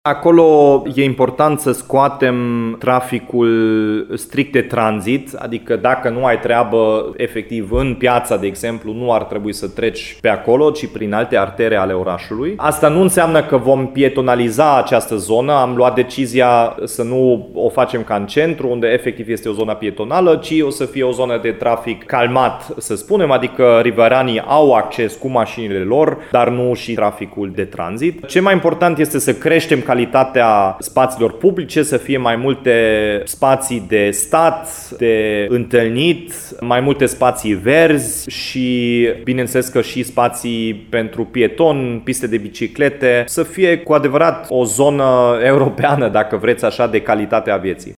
Proiectele urmăresc reinventarea zonelor istorice din oraș, spune primarul Dominic Fritz, care mai subliniază că vor aduce un confort sporit locuitorilor dar și vizitatorilor, mai precizează edilul.
Dominic-Fritz-1.mp3